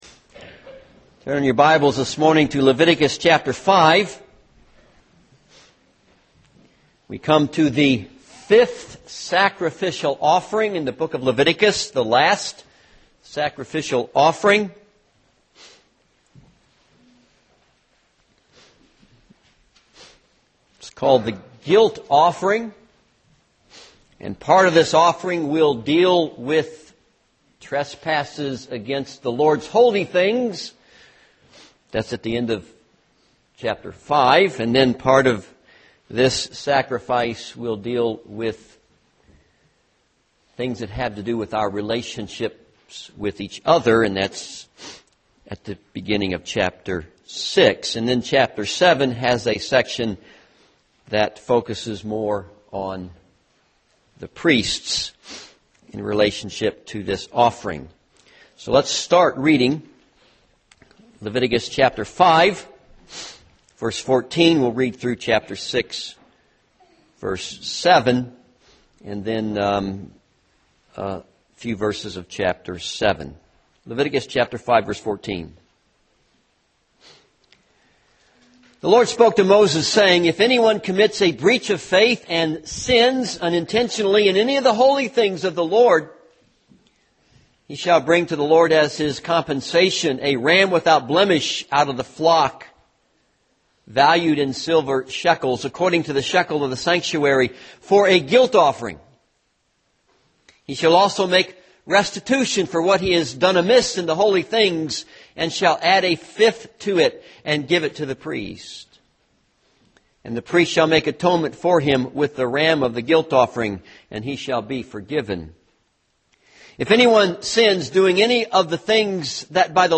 This sermon is based on Leviticus 5:14-6:7 and Leviticus 7:1-10.